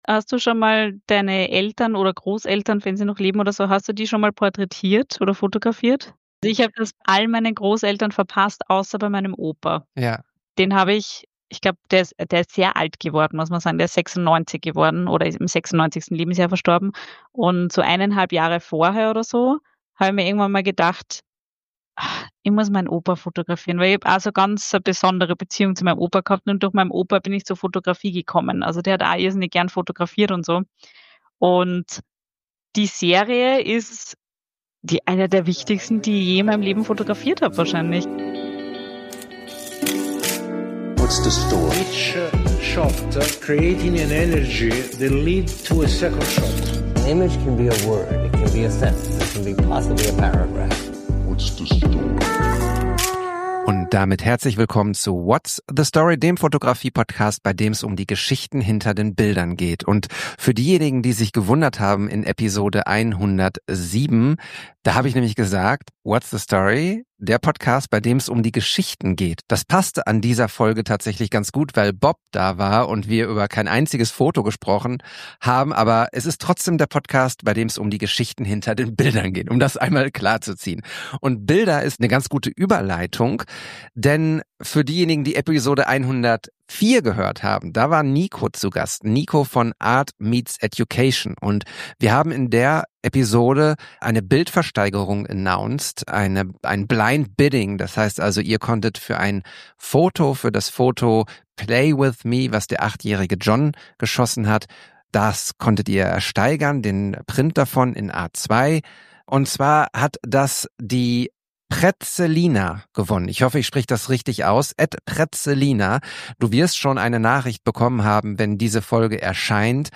LIVE von der Imaging World